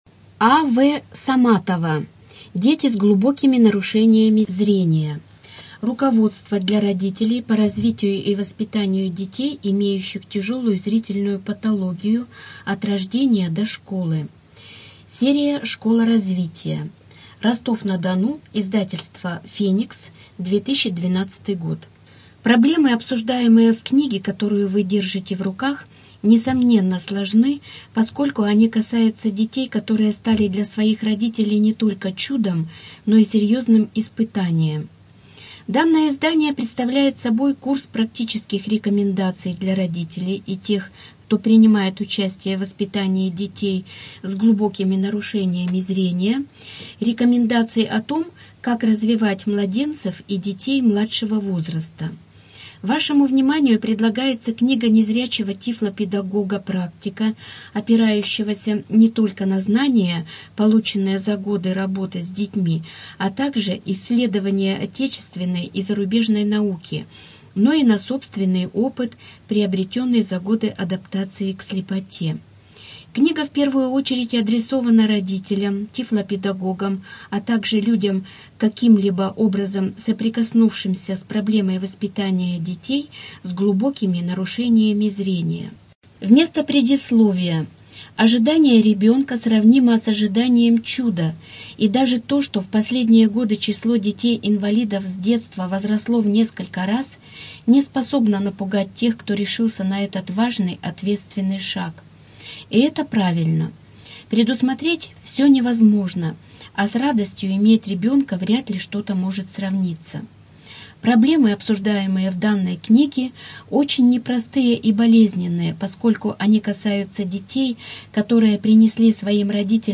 Студия звукозаписиКрасноярская краевая библиотека для слепых